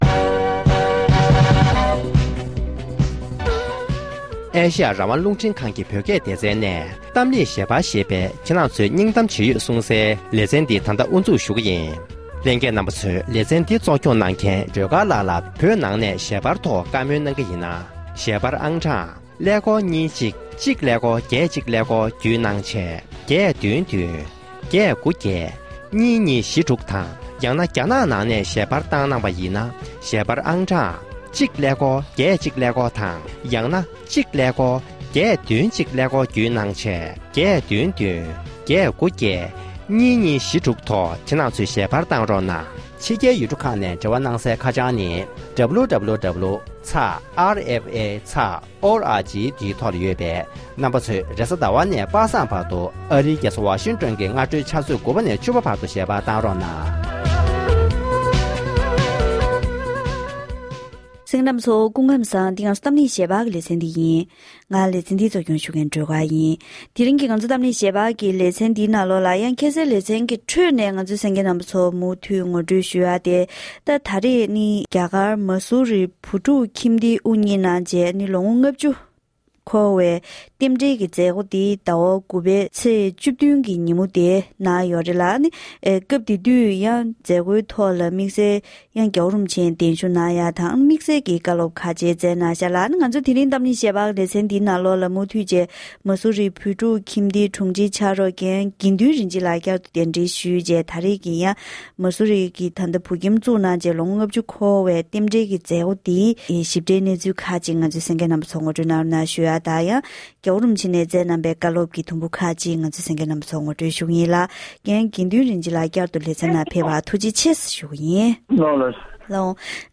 རྒྱ་གར་གྱི་ཨུཏ་ཏར་ཁཎྜ་མངའ་སྡེའི་ནང་རྟེན་གཞི་བྱེད་པའི་མ་སུ་རི་བོད་ཕྲུག་ཁྱིམ་སྡེ་དབུ་བརྙེས་ནས་ལོ་༥༠འཁོར་བའི་མཛད་སྒོའི་ཐོག་༧གོང་ས་མཆོག་ཆིབས་བསྒྱུར་གྱིས་བཀའ་སློབ་གནང་ཡོད་པ་རེད།